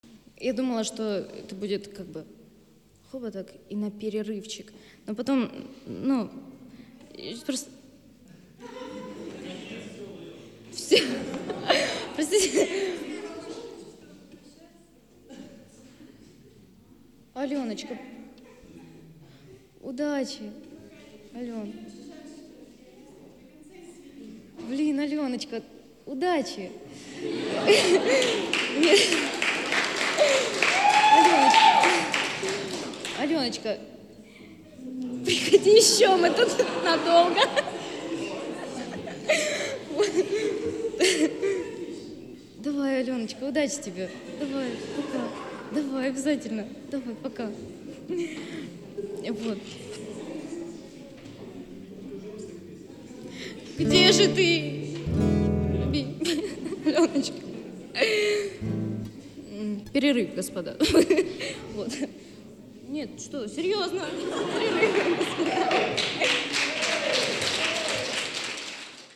записанный на концерте пятого мая в галерее "Юг", г. Краснодар